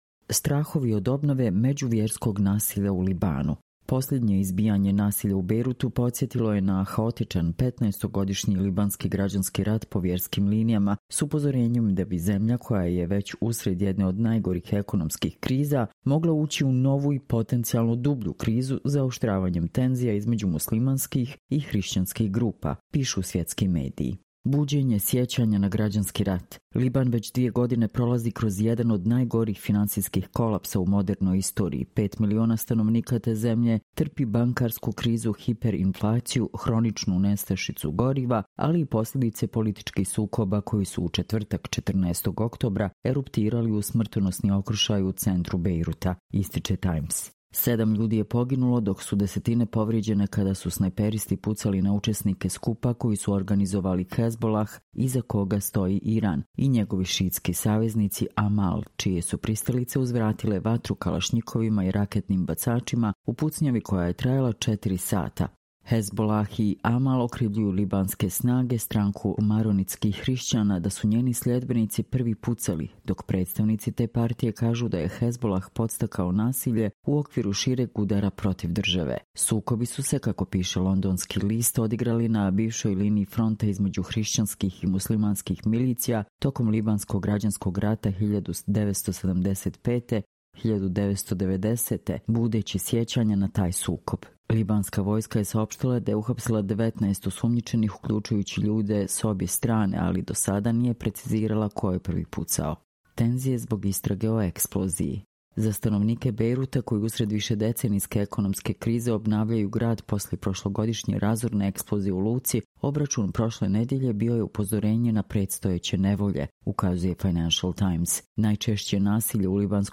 Čitamo vam: Strahovi od obnove međuverskog nasilja u Libanu